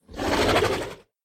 MinecraftConsoles / Minecraft.Client / Windows64Media / Sound / Minecraft / mob / horse / zombie / idle3.ogg